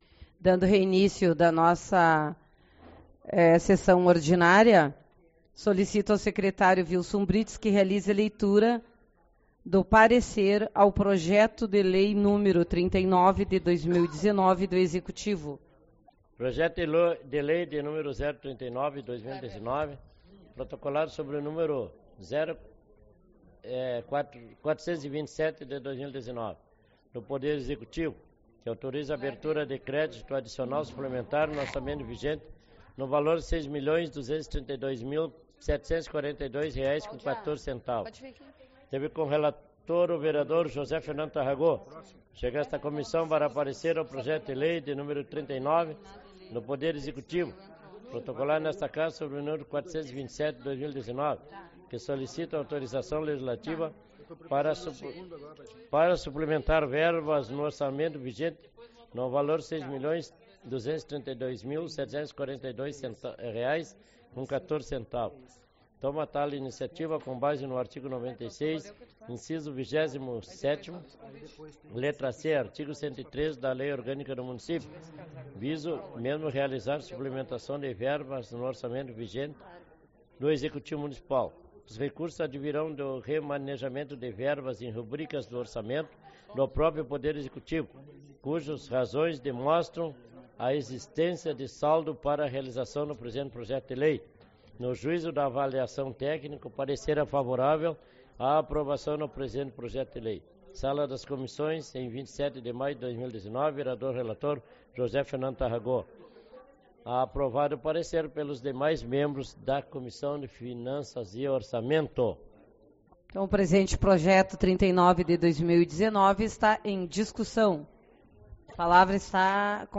30/05 - Reunião Ordinária / Parte 2